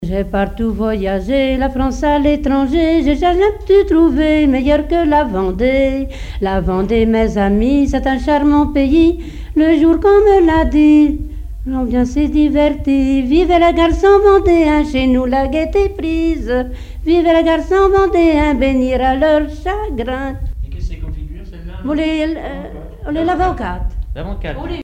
Chants brefs - A danser
danse : quadrille : avant-quatre
Répertoire d'airs à danser
Pièce musicale inédite